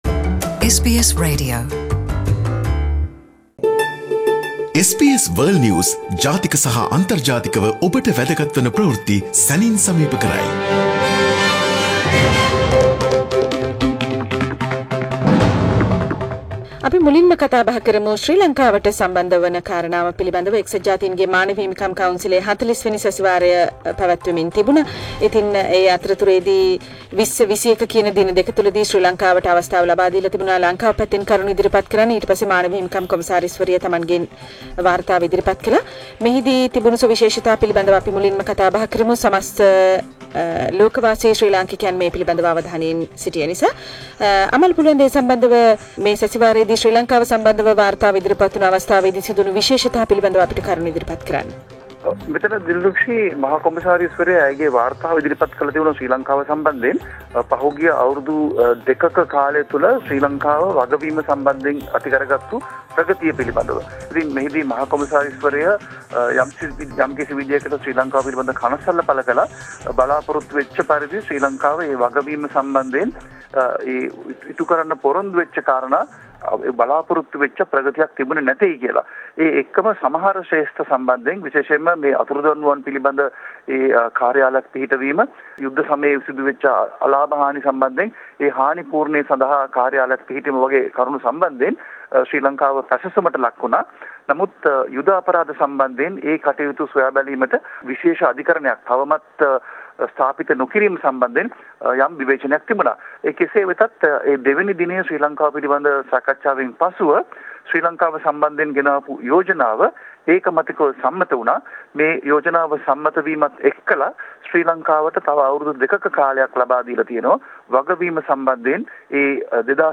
විදෙස් විත්ති සමාලෝචනය - "ලොව වටා